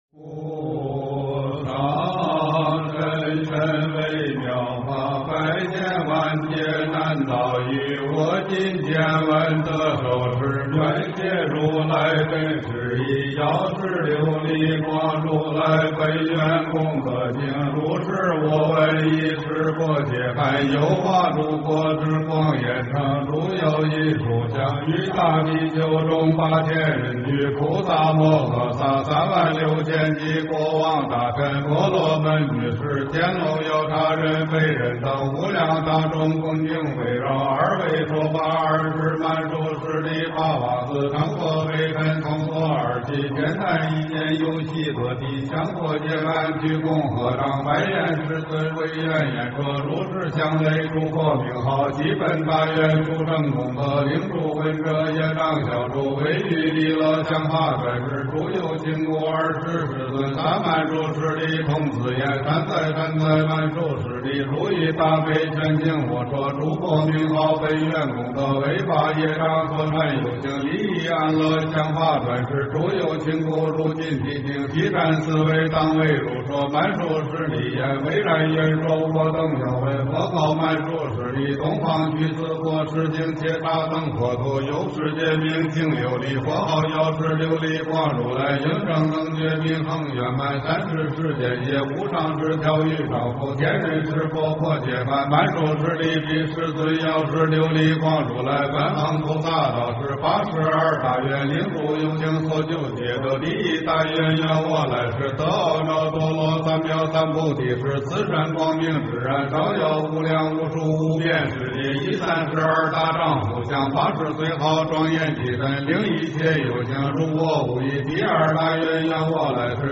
药师经 - 诵经 - 云佛论坛
佛音 诵经 佛教音乐 返回列表 上一篇： 佛说八大菩萨经 下一篇： 佛说阿含正行经 相关文章 吉祥飞扬-准提神咒--关牧村 吉祥飞扬-准提神咒--关牧村...